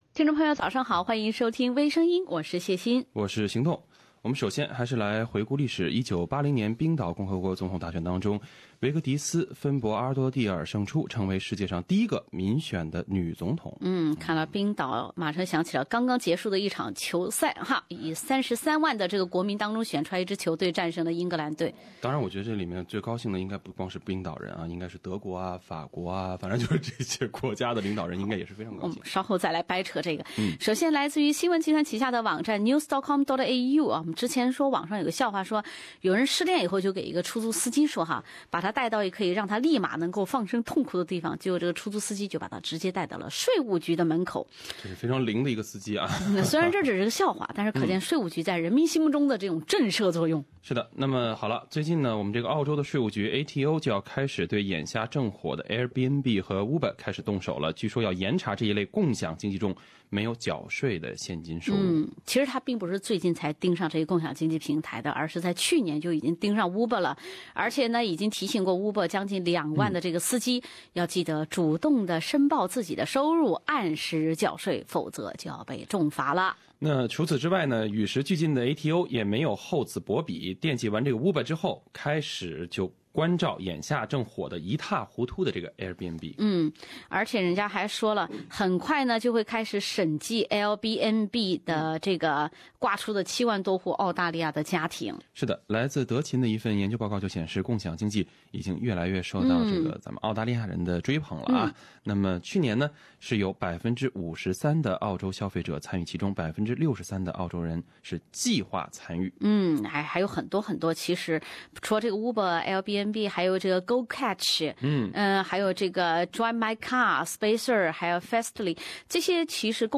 另類輕松的播報方式，深入淺出的辛辣點評；包羅萬象的最新資訊；傾聽全球微聲音。